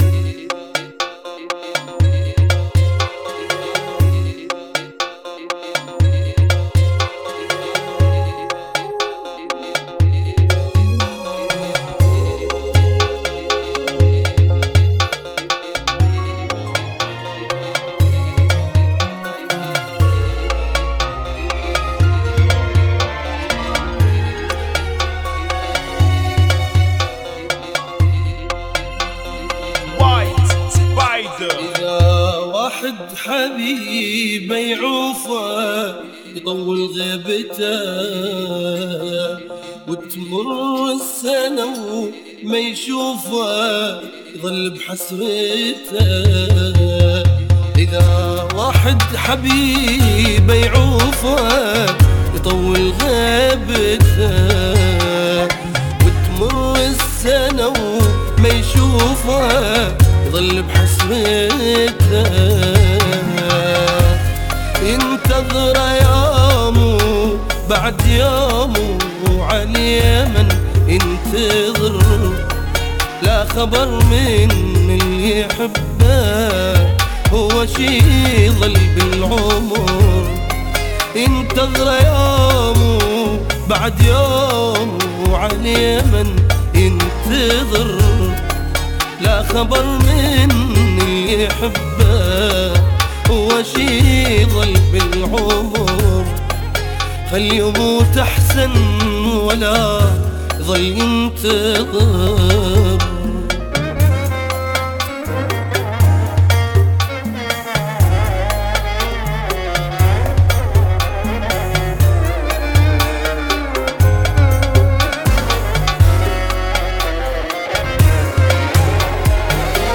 Funky [ 60 Bpm ]